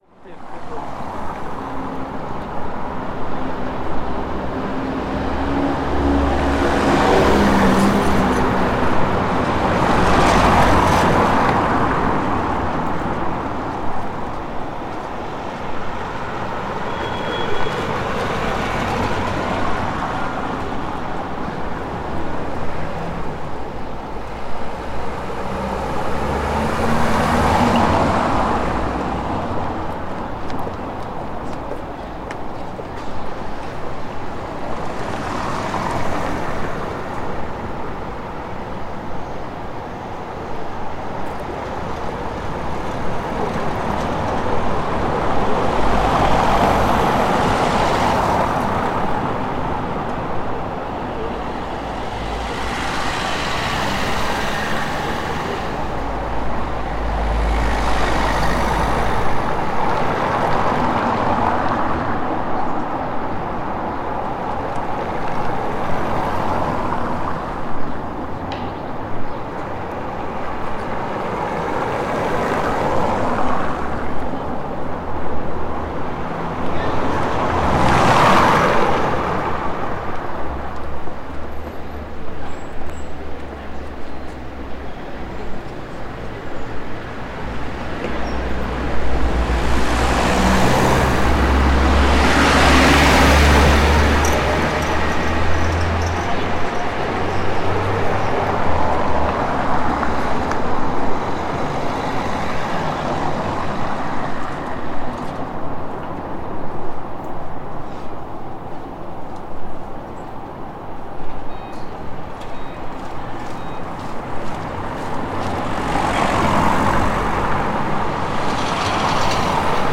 Gravação do ruído dos automóveis na Av. Capitão Silva Pereira e em particular do som do contacto dos pneus dos automóveis na calçada de granito. Gravado com FR-2LE e um microfone Tellinga.
NODAR.00090 – Viseu: Av. Capitão Silva Pereira- Carros passam na calçada
Tipo de Prática: Paisagem Sonora Rural
Viseu-Av.-Capitão-Silva-Pereira-Carros-passam-na-calçada.mp3